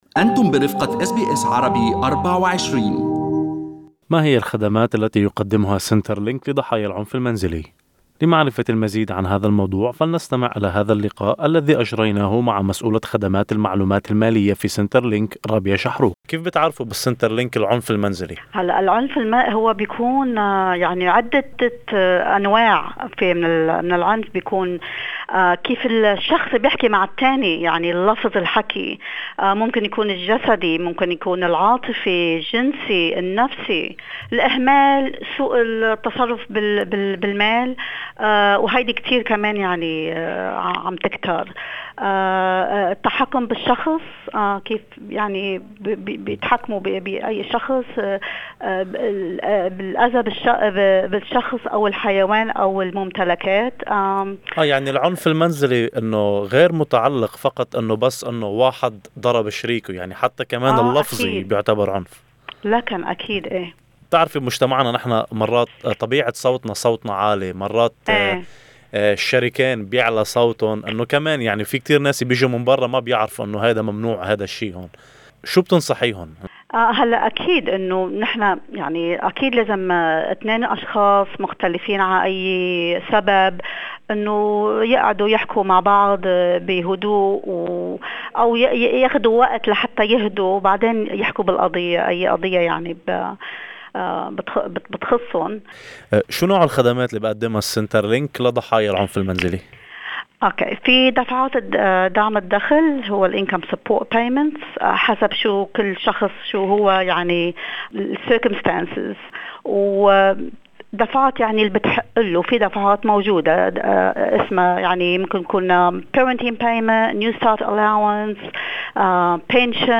يمكنكم الاستماع إلى هذا اللقاء كاملا عبر الضغط على الملف الصوتي أعلاه.